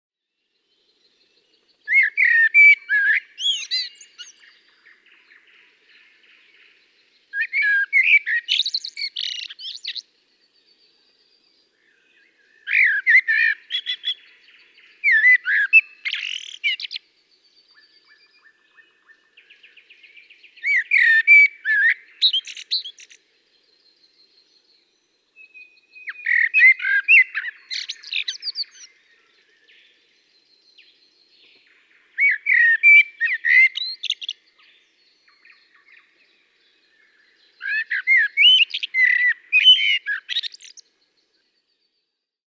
Mustarastaan kaunista huilumaista laulua kuulee usein jo varhain keväällä, tai jopa lopputalvella.